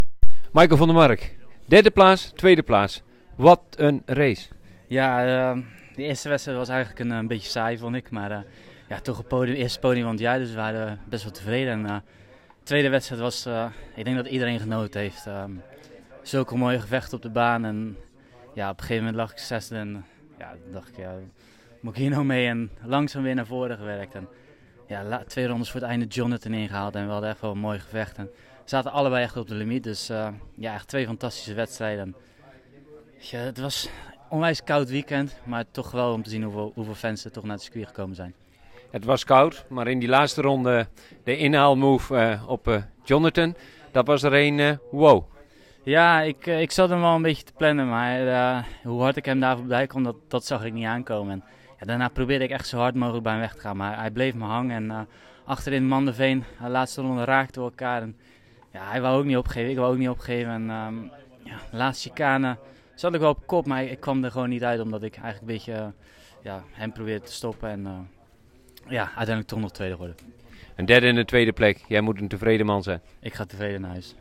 Na afloop spraken we met een blije Van der Mark.